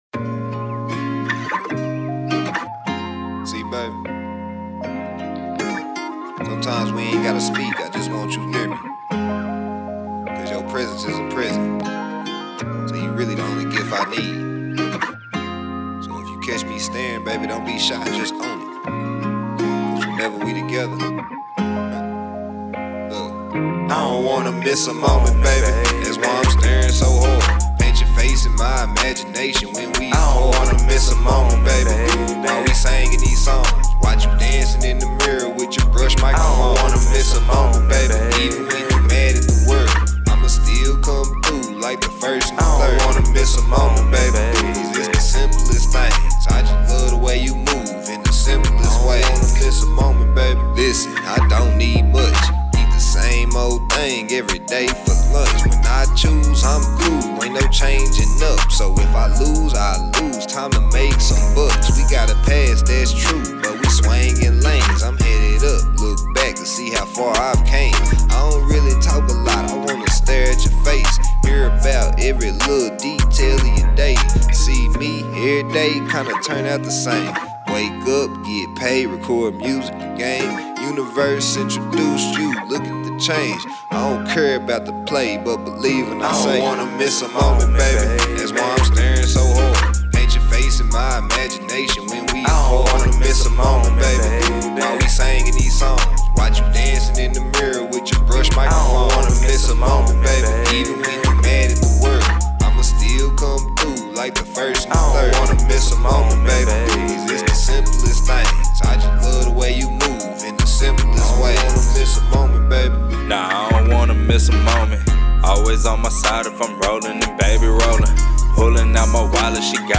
The Vibe: Deep Connection.